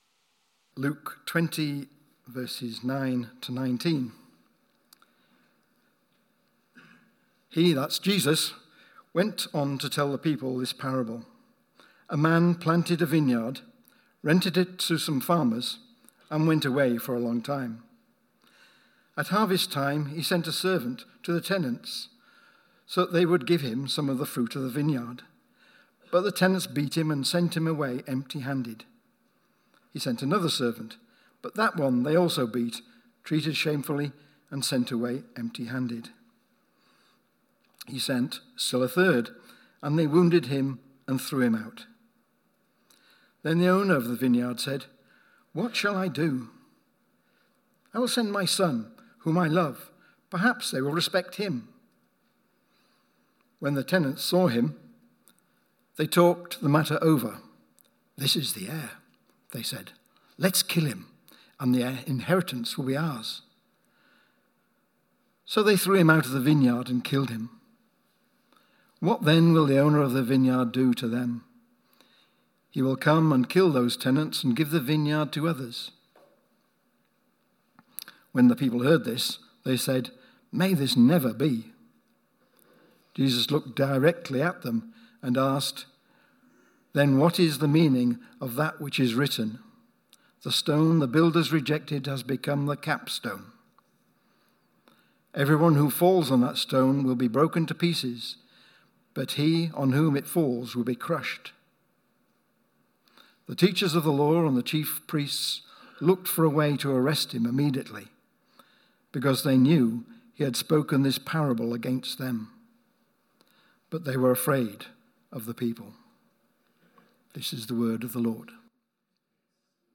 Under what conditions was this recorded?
Media for Sunday Service on Sun 29th Jan 2023 10:00